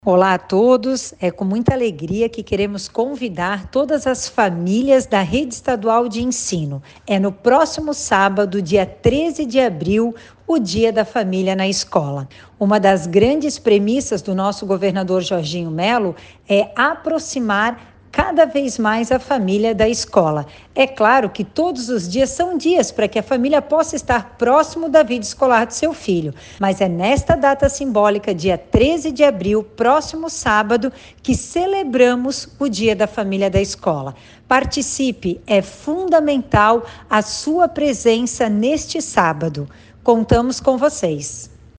A secretária adjunta da Educação, Patrícia Lueders, deixa o convite aos familiares e reforça que todos os dias a família pode estar próxima da vida escolar de seu filho:
SECOM-Sonora-secretaria-adjunta-da-Educacao-Dia-da-Familia-na-escola.mp3